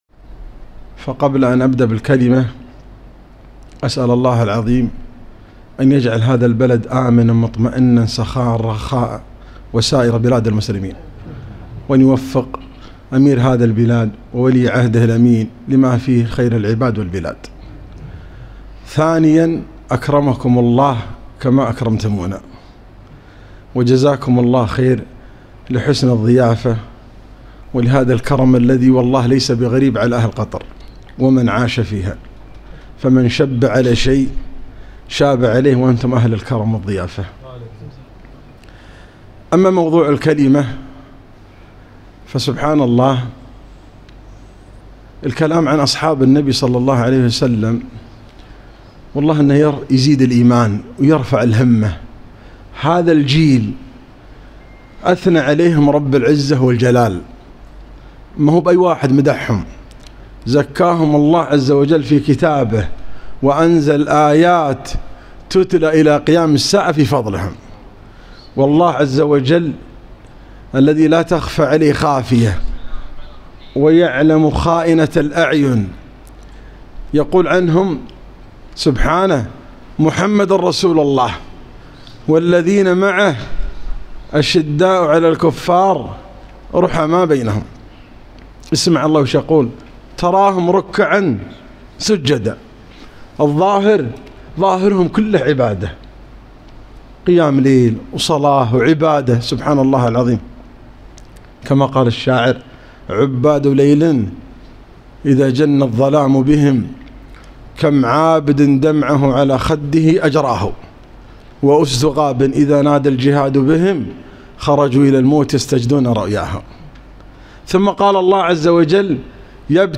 محاضرة - رجال صدقوا ما عاهدوا الله عليه